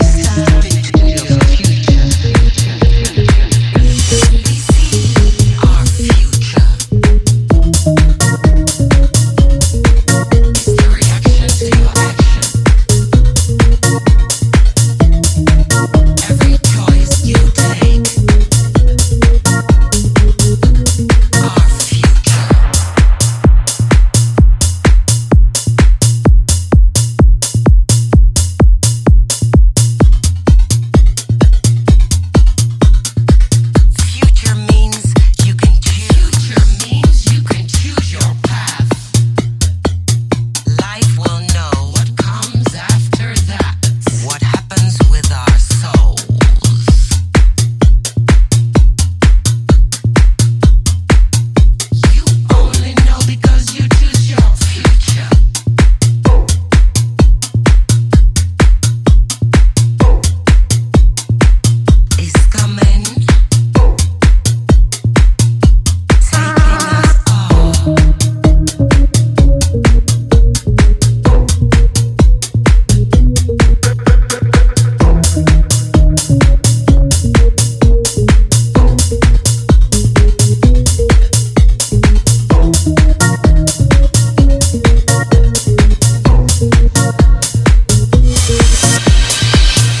どちらも強力ですが、よりソリッドかつフロアを煮詰めるような深夜向けの展開にシェイプされた後者が、さすがの仕事振りですね！